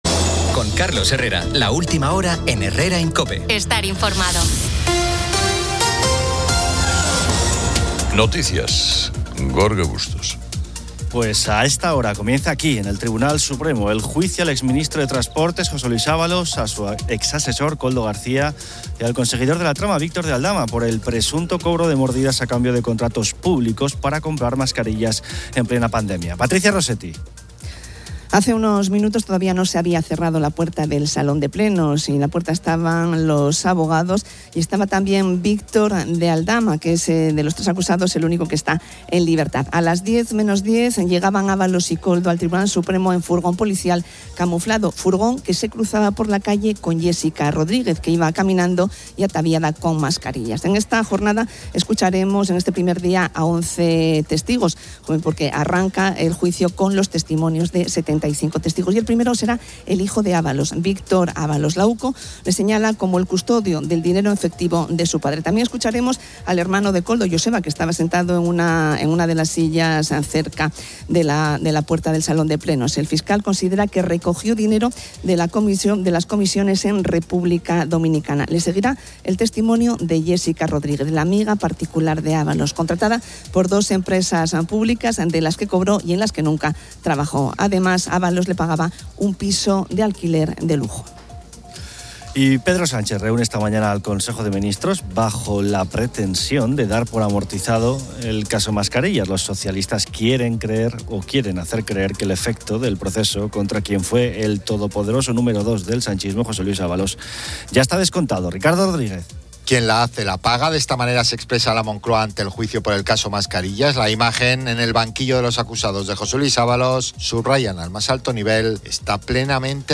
Además, Asturias sufre 32 incendios forestales activos, con toda la comunidad en riesgo alto por las altas temperaturas, mientras los bomberos luchan contra las llamas y se esperan tormentas. Un espacio significativo aborda las peculiaridades que hacen que la pareja pierda el atractivo, con oyentes que comparten anécdotas sobre ronquidos, vestimenta, manías o posturas, y una reflexión sobre las imperfecciones en el amor.